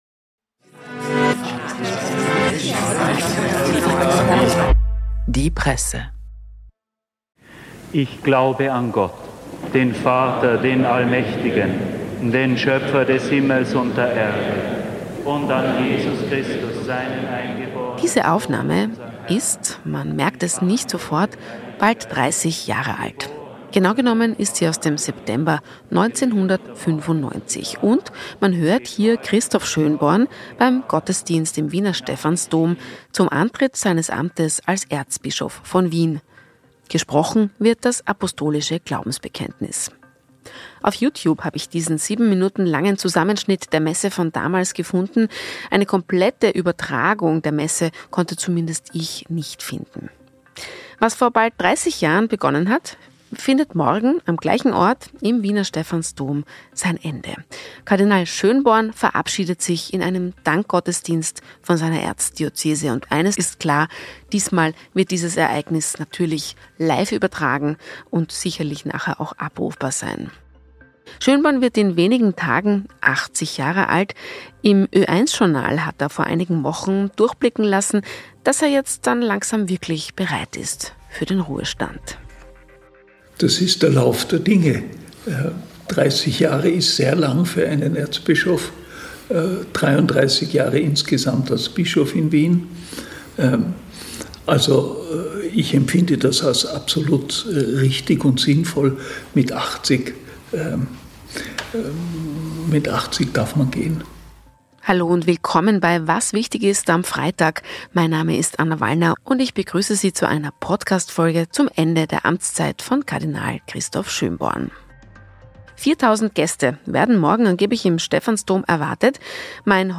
Der Nachrichten-Podcast der Tageszeitung "Die Presse" erscheint wochentags Montag bis Freitag um 18 Uhr und zu besonderen Anlässen wie an Wahlsonntagen auch zwischendurch. Die Redaktion der "Presse" sagt, was wichtig ist und nimmt Sie mit auf Recherche und zu spannenden Gesprächspartnern.